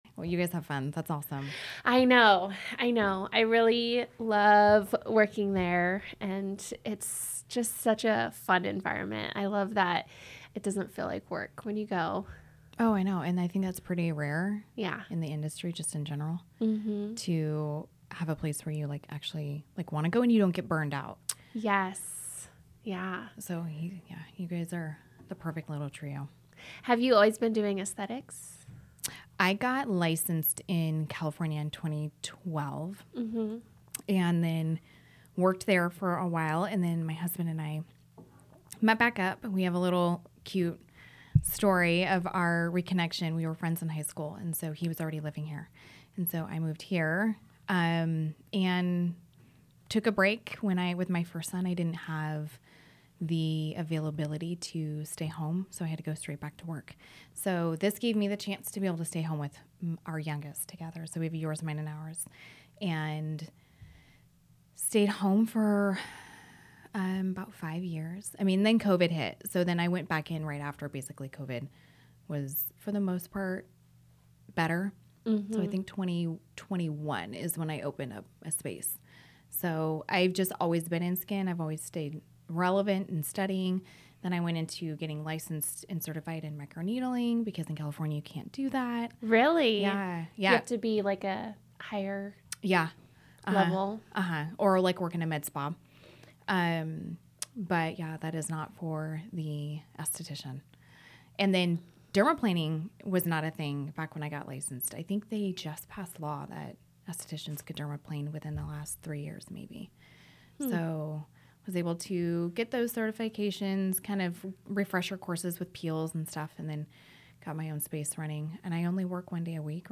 In this enlightening episode, two skincare experts discuss working in a fun, supportive environment and delve deep into the world of aesthetics. The conversation transitions into nutrition's impact on skin health, the role of processed foods, and their journey into holistic nutrition. They stress the importance of educating oneself on skincare products, avoiding fads on social media, and seeking advice from licensed professionals.